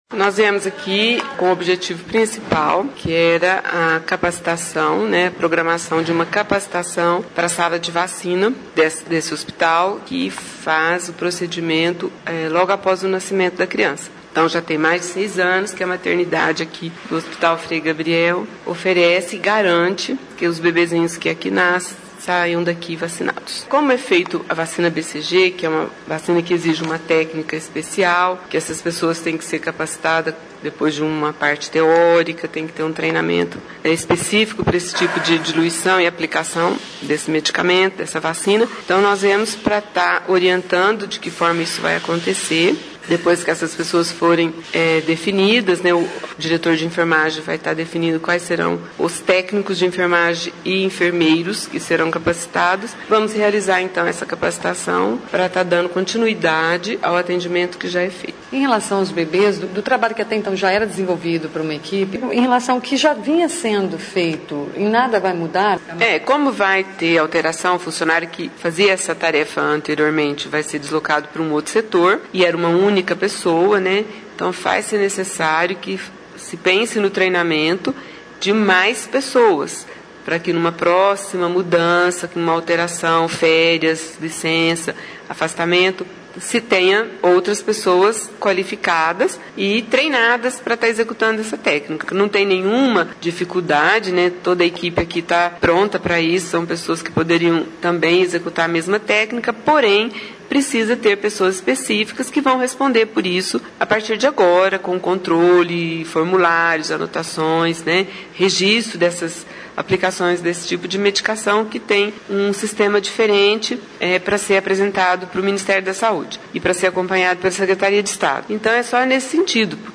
Profissionais de saúde do Hospital Frei Gabriel passaram por uma reunião para a organização de um treinamento para que alguns dos profissionais recebam a capacitação para a sala de vacinas. (Clique no player abaixo e ouça a entrevista). Essa parte cuida do processo de imunização logo após o nascimento das crianças e uma das vacinas, a B.C.G., exige uma técnica especial de diluição e aplicação.